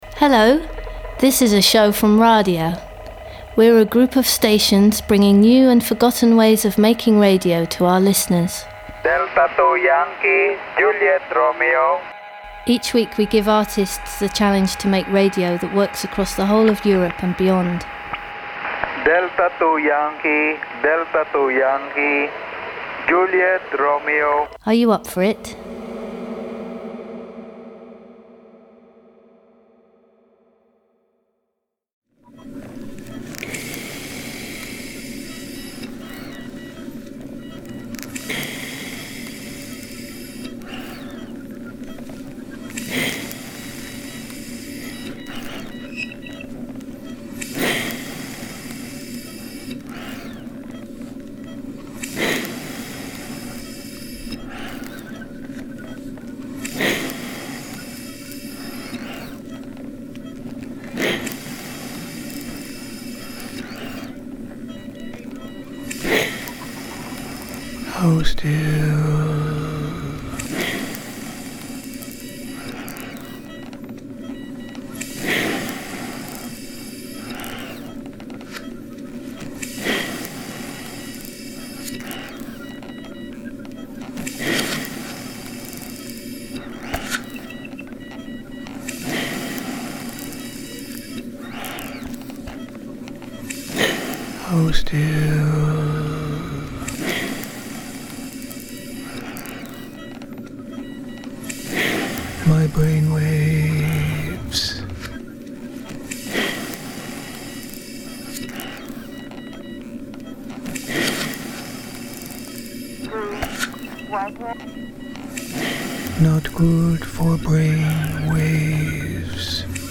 With a title that descends from a well-known passage in the Tibetan Book of the Dead, May it come that the sounds of the bardo shall be known as one’s own sounds, the piece opens with an improvised DIY ventilator that fails to sustain my bravewaves, waves that eventually flatline into a heavily decomposed electromagnetic soup. Then comes a freely associative consideration of the Beefheart Affliction; the dangerous game of creating schizophonic monsters; the compost heap of radio’s intrinsic entropy and instability; the creative possibilities released through the fractures of a broken subjectivity; a synthetic voice ripened, to the point of bursting; a piece of flesh that we shall call Figgy Pudding; a bit of brain beneath a fingernail; a scratched radio thanatophony; and a pair of eyes reflecting a Hegelian Night that becomes —- awful.